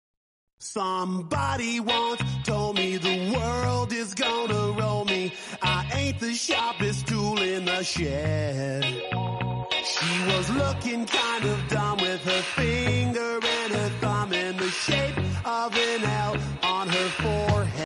Donald Duck is Screaming
You Just Search Sound Effects And Download. tiktok sound effects funny Download Sound Effect Home